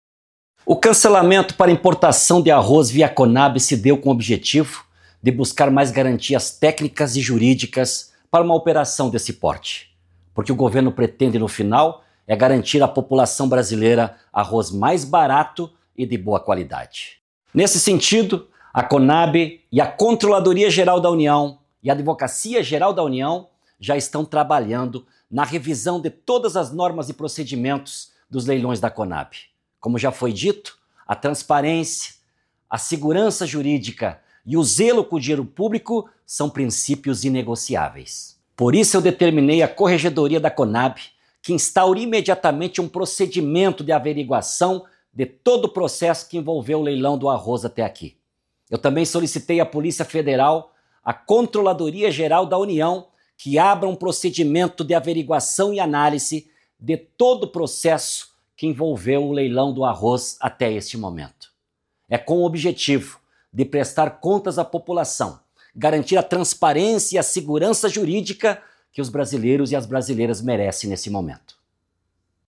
Pronunciamento-Presidente-Edegar-Pretto-Conab.mp3